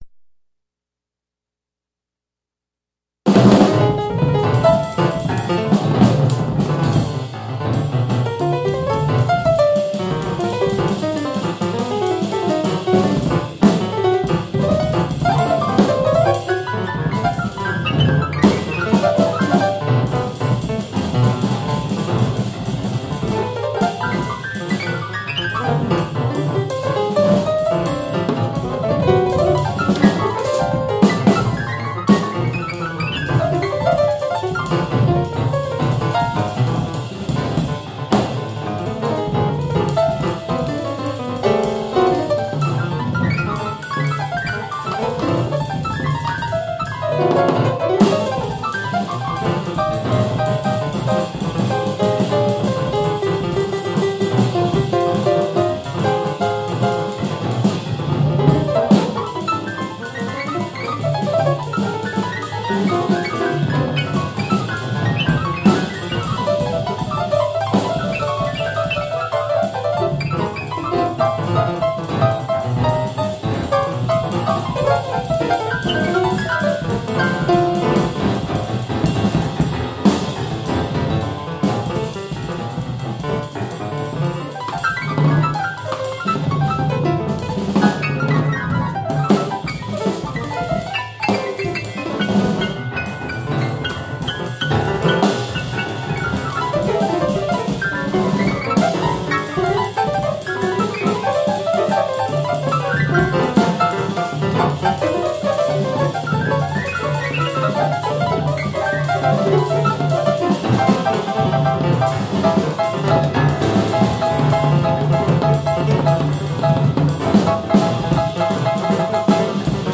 A third major figure in free jazz died this week....